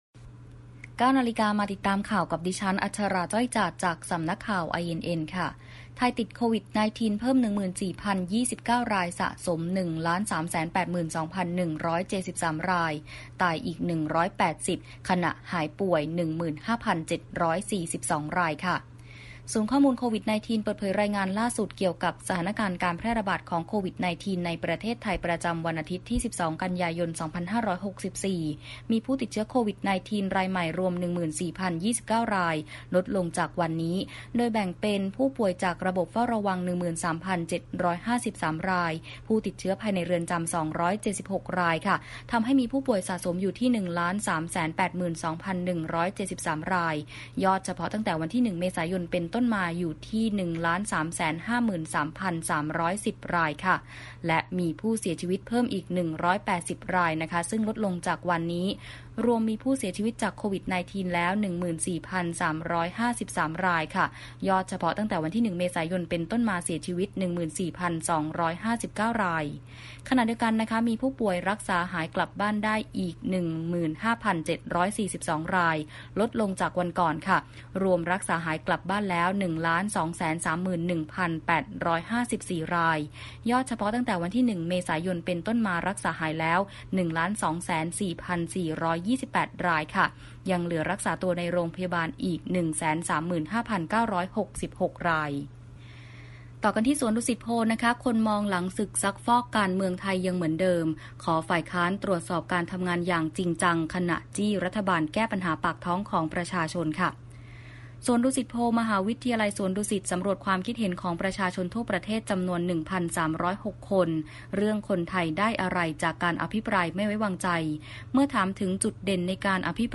ข่าวต้นชั่วโมง 09.00 น.